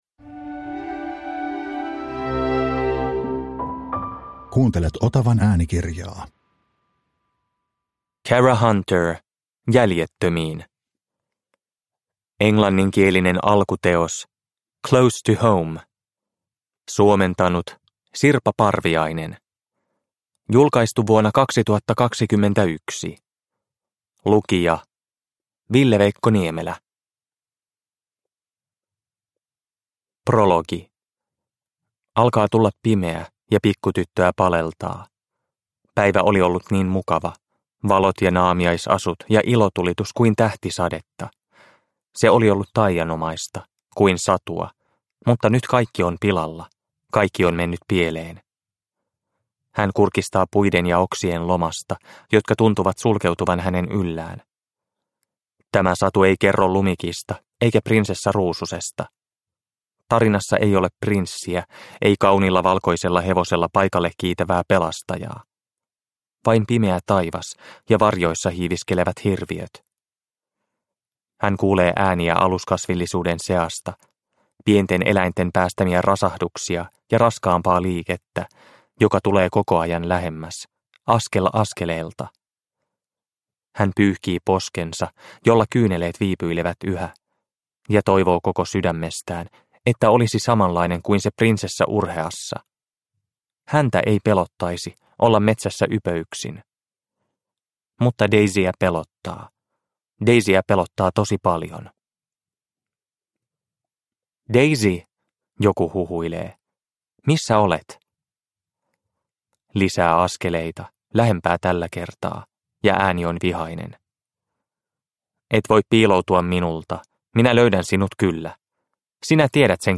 Jäljettömiin – Ljudbok – Laddas ner